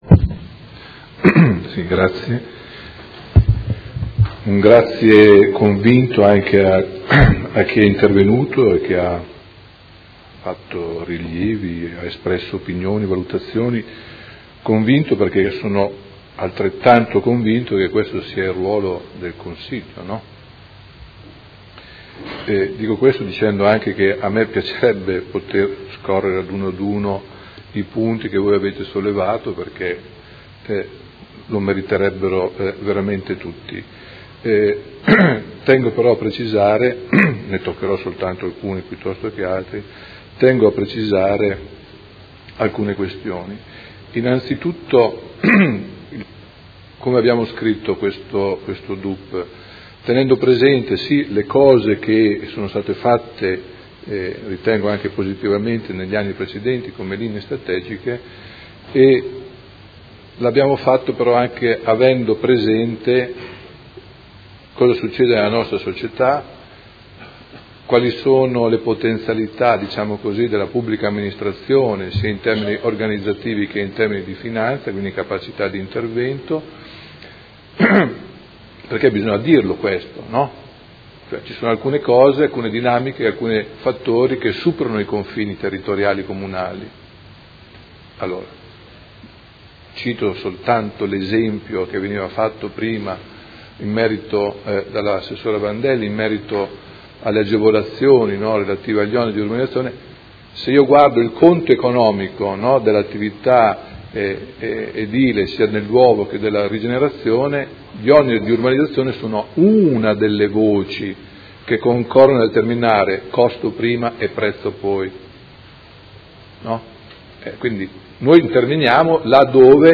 Seduta del 26/09/2019. Replica al dibattito su proposta di deliberazione: Documento Unico di Programmazione 2020-2022 - Approvazione